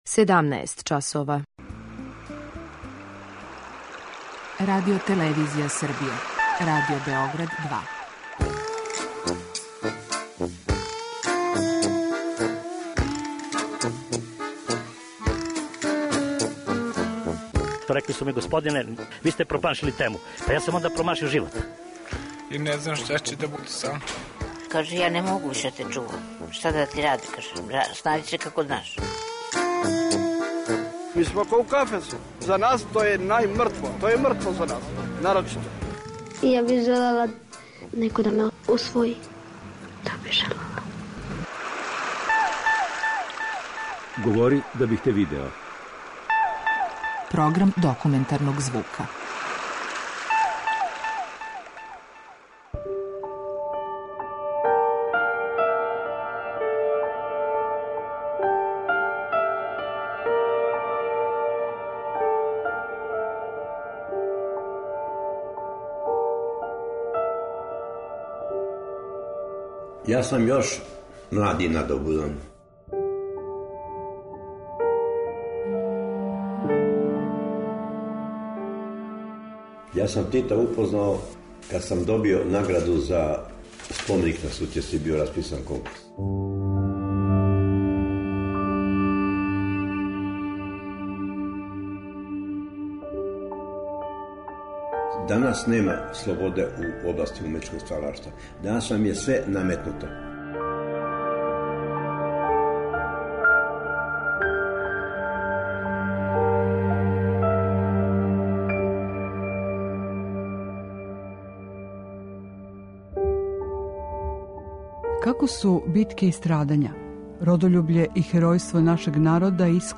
Документарни програм
Умро је у 93. години живота, а репортажа коју ћете чути, забележена је 2016. године у атељеу Миодрага Живковића Жилета.